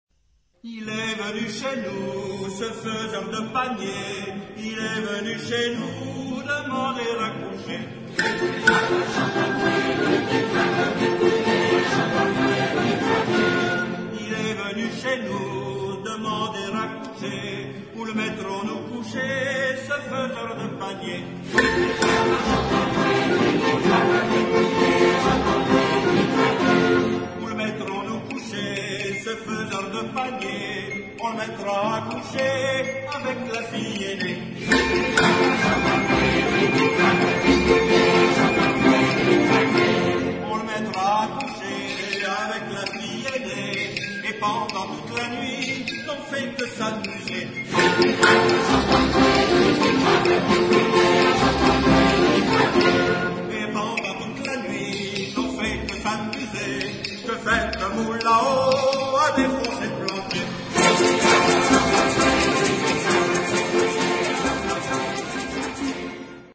Genre-Stil-Form: Volkstümlich ; weltlich
Chorgattung: SATB  (4 gemischter Chor Stimmen )
Tonart(en): F-Dur
Lokalisierung : Populaire Francophone Acappella